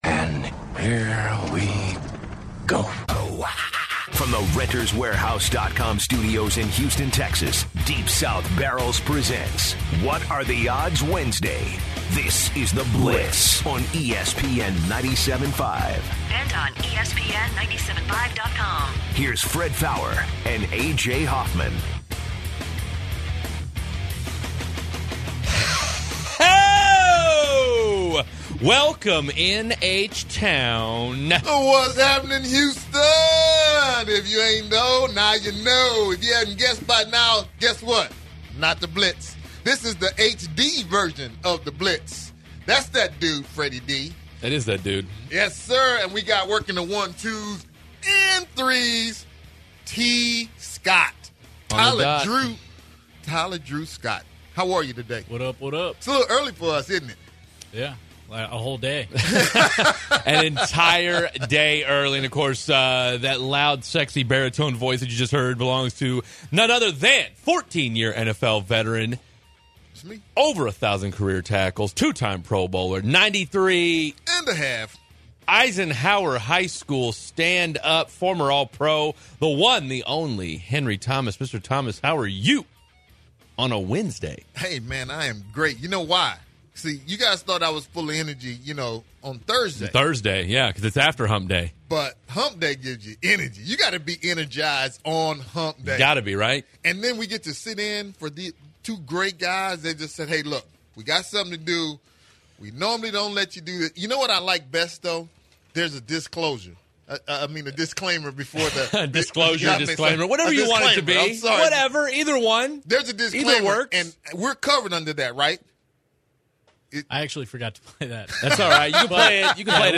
The guys are joined by boxer Terence Crawford to discuss his upcoming fight.